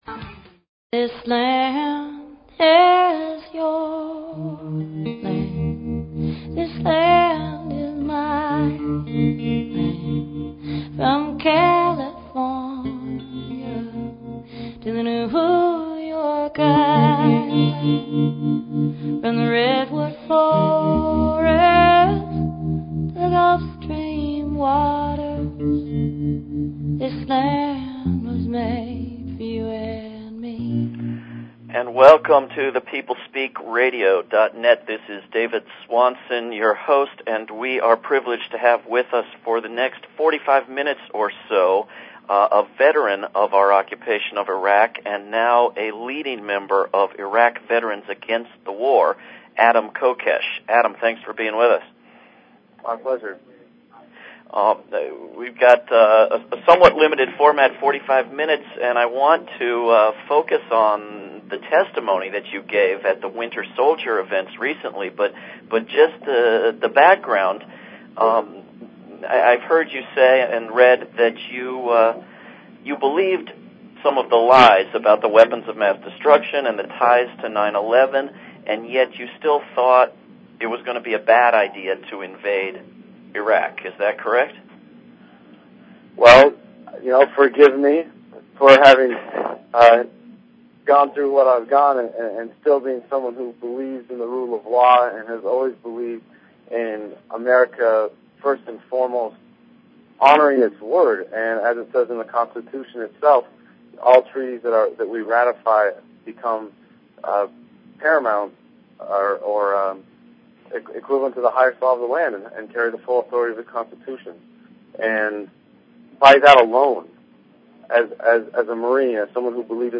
Talk Show Episode, Audio Podcast, The_People_Speak and Courtesy of BBS Radio on , show guests , about , categorized as
The show features a guest interview from any number of realms of interest (entertainment, science, philosophy, healing, spirituality, activism, politics, literature, etc.).